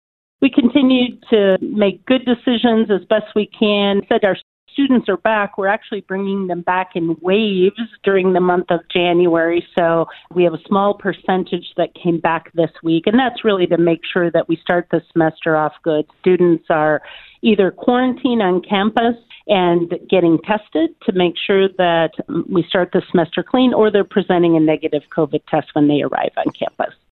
was a guest on the KHUB Morning Show on Thursday